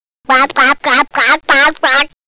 Wer nochmal mitraten möchte, kann sich ja zuerst die Tierstimmen anhören.